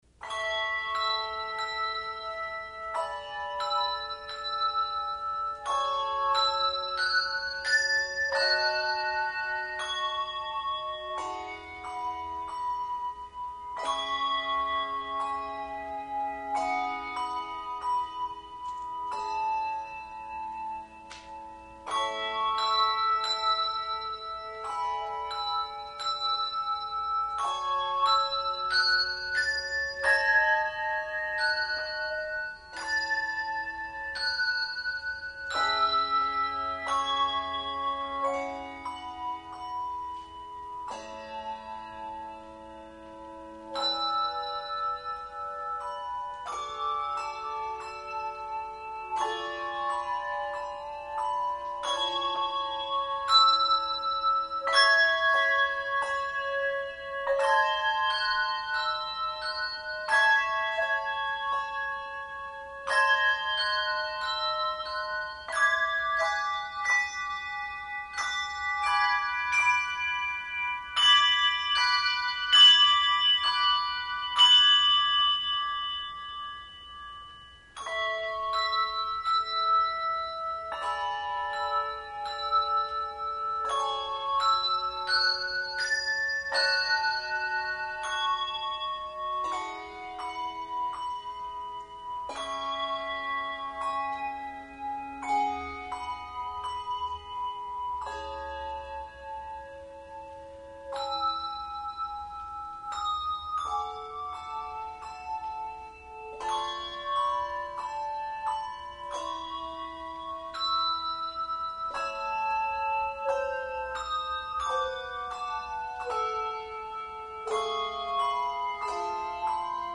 Handbell Quartet
quiet, contemplative composition
Delicate and charming.
Genre Secular
No. Octaves 3 Octaves